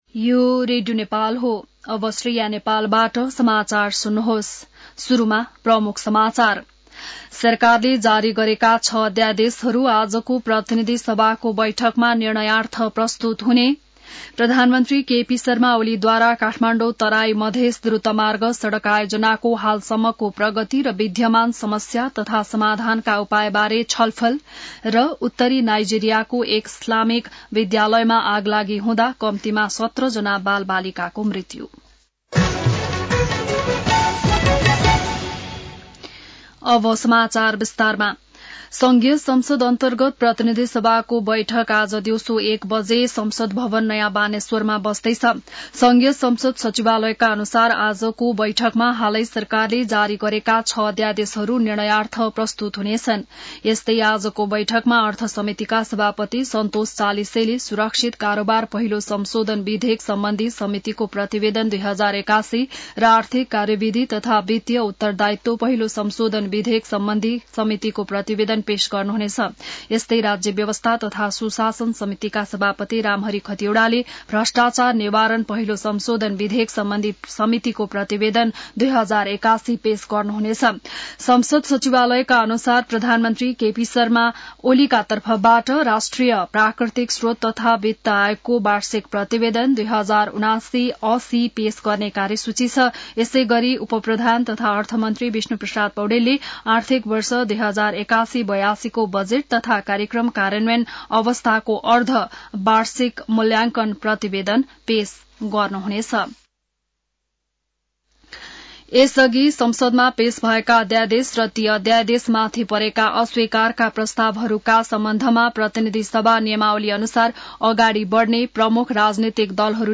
बिहान ९ बजेको नेपाली समाचार : २५ माघ , २०८१